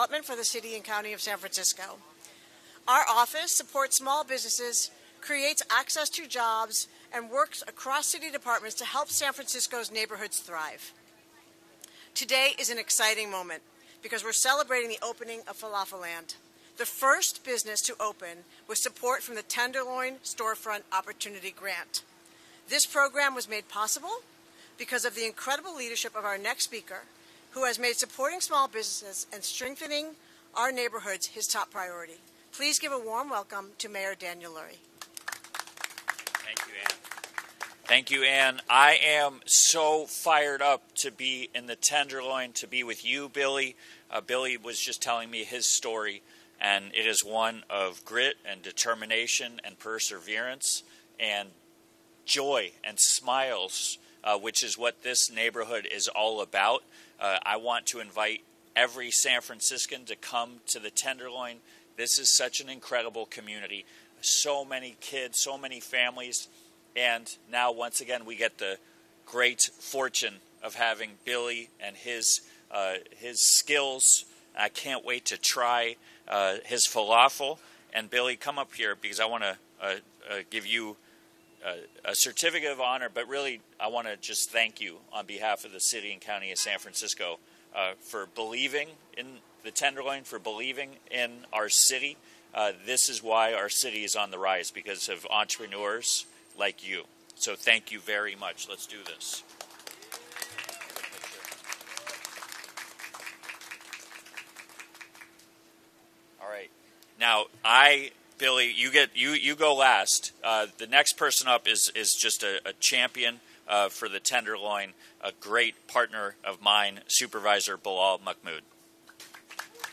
Falafelland Ribbon Cutting - Dec 18, 2025